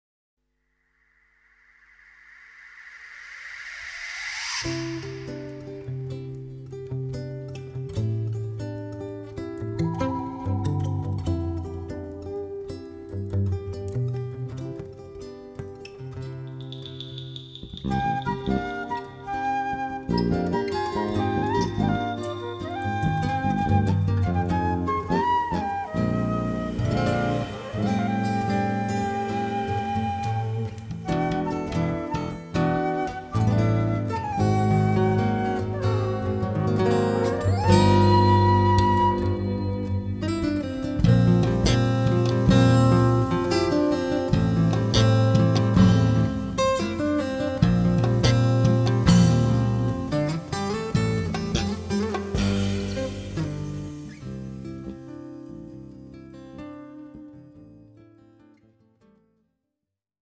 electro-acoustic guitar, electric guitar, vocals
percussion, congas, bongos, darabuka, udu, timbales
electric bass, fretless bass
soprano & tenor saxophone sax, bamboo flute
piano, keyboards
violon
Recorded in 1987